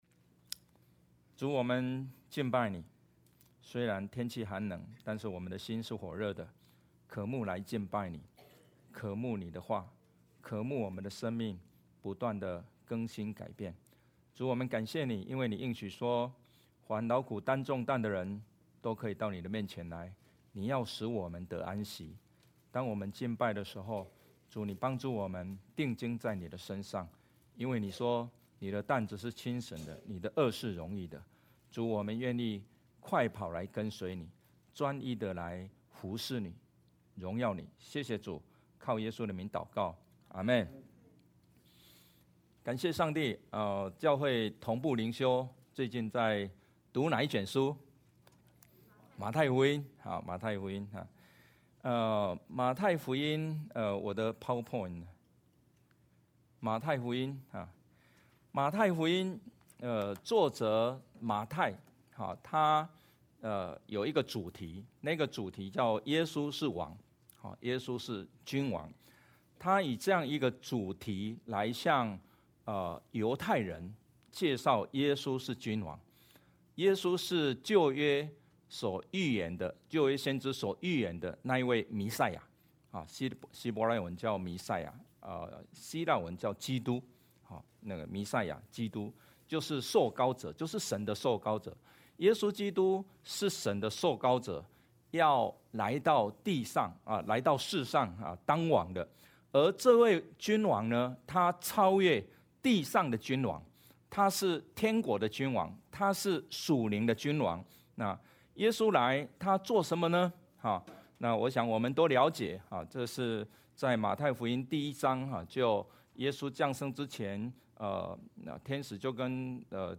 Matthew 9:1–13 Service Type: 主日证道 Download Files Notes « 为此蒙召 榮神益人 » Submit a Comment Cancel reply Your email address will not be published.